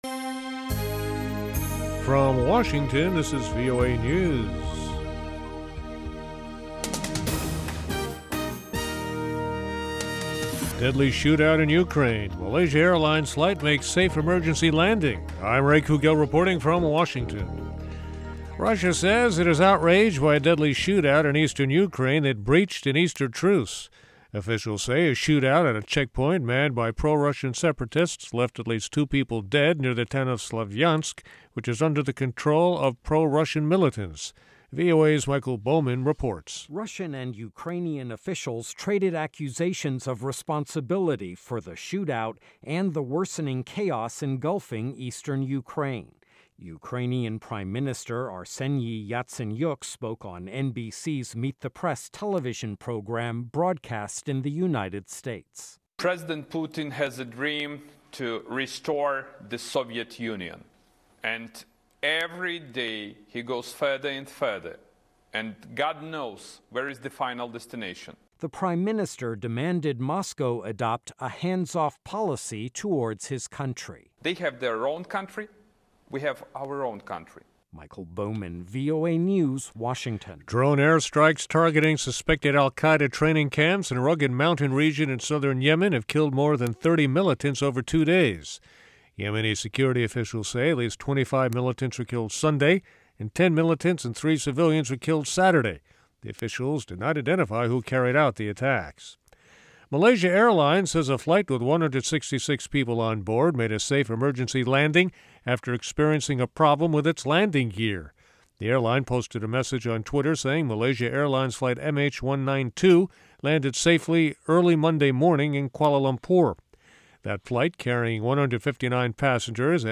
Urdu Headlines